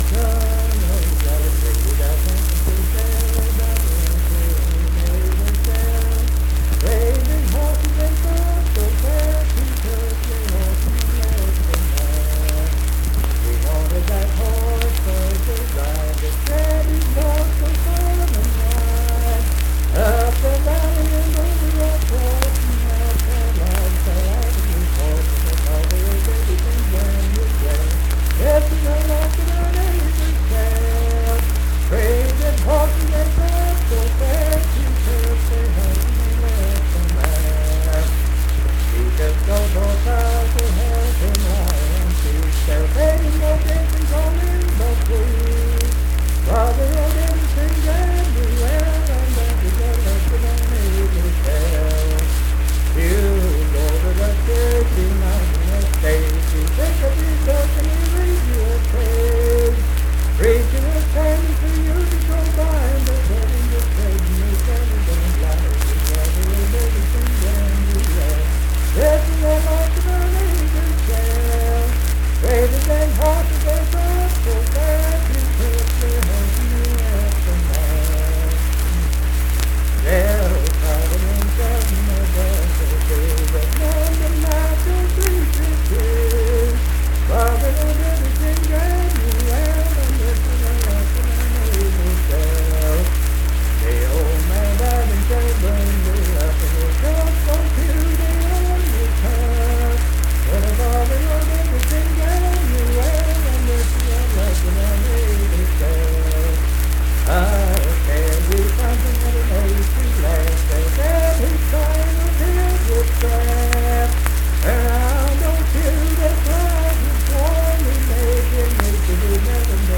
Unaccompanied vocal music
Verse-refrain 8(4).
Performed in Kanawha Head, Upshur County, WV.